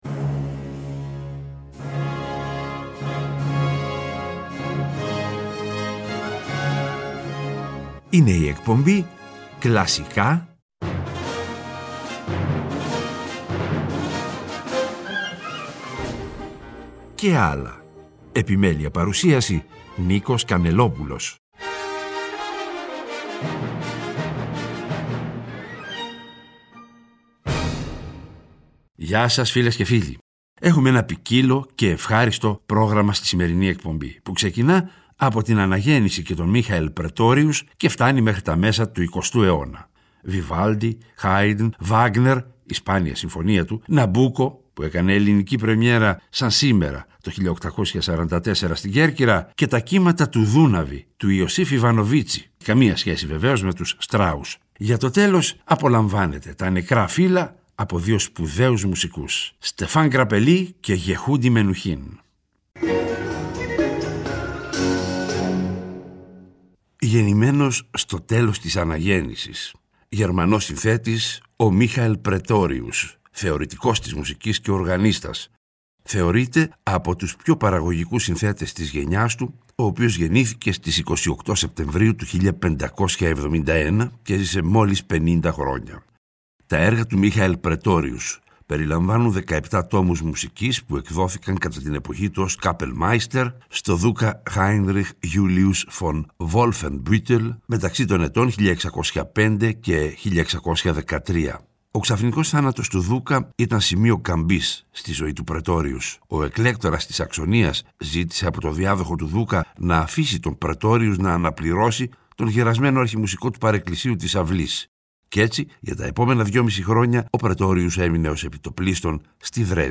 βιόλα & βιολί